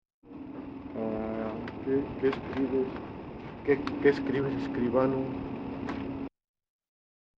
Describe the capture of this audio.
que escrives? 7 sec. mono 74k